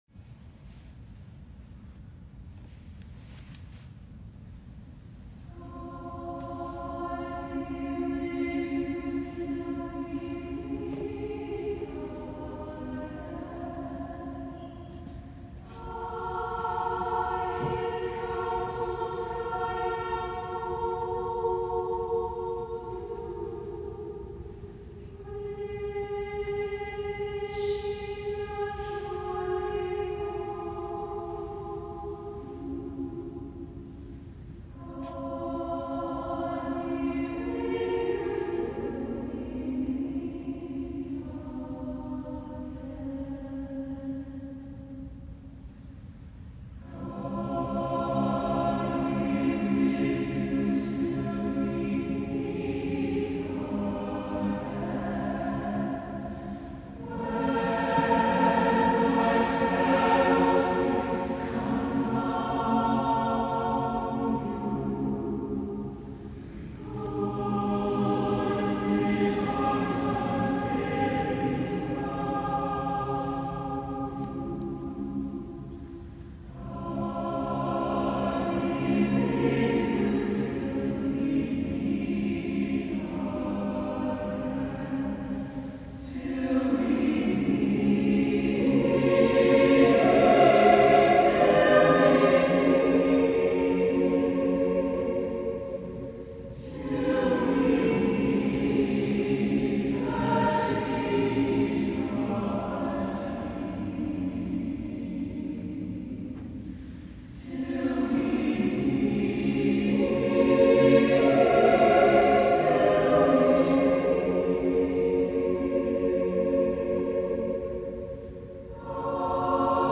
God Be with You Till We Meet Again” arranged by Larry Mayfield.
The piece has been a tradition at the end of programs as a way to send the congregation out.